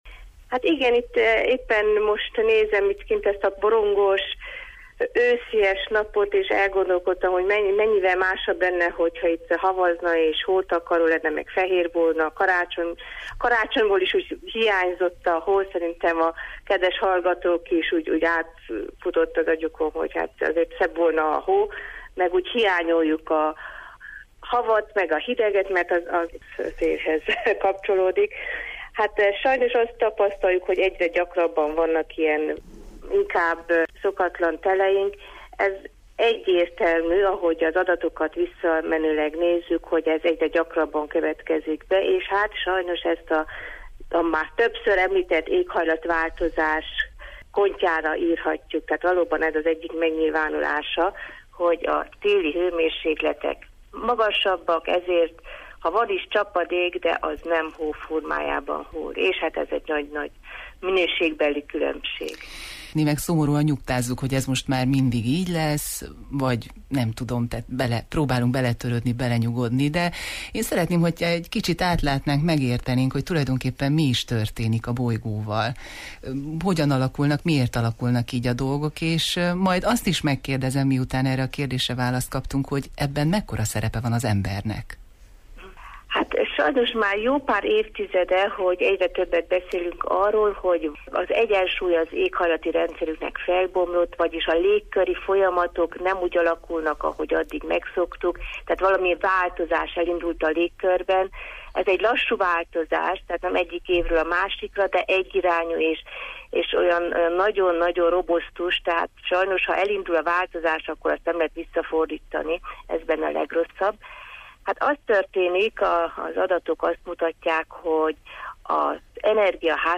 Szépkorú hallgatóinkat kérdeztük arról, hogy mire emlékeznek azokból a januárokból, amikor csattogtak a fák ágai a fagytól, a hidegtől.